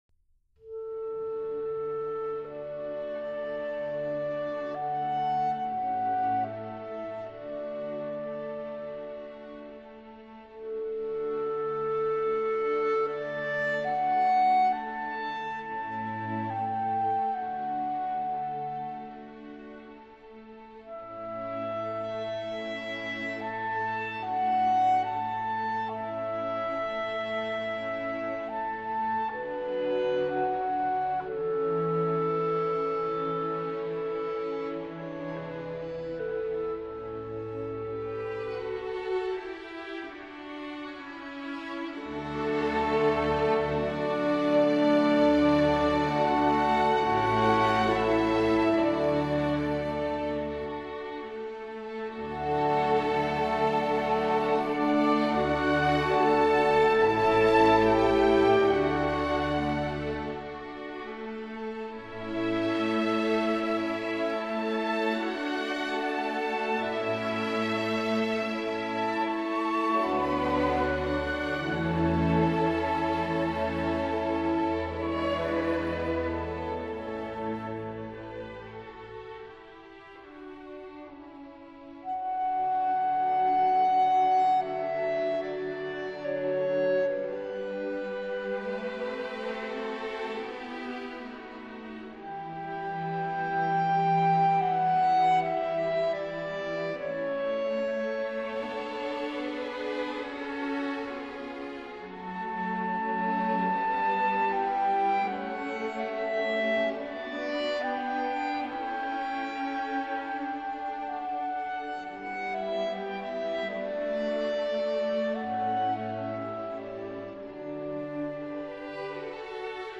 clarinet & conductor